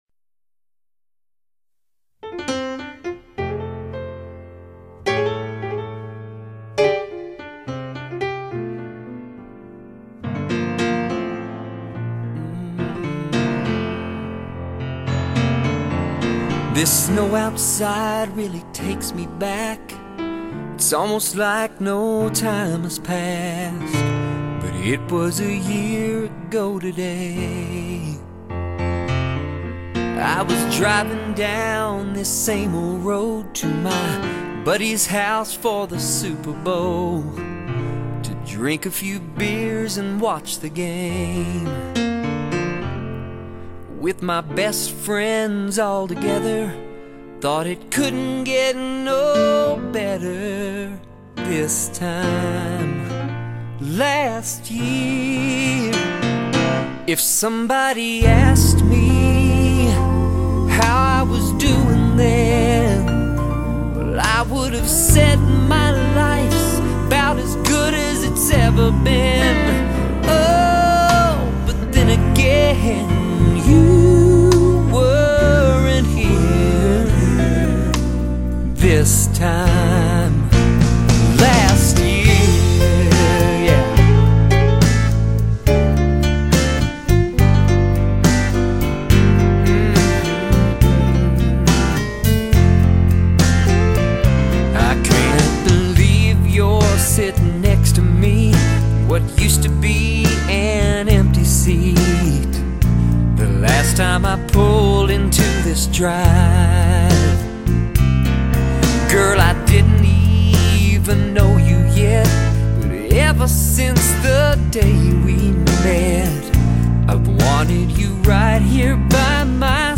80s and 90s country and classic rock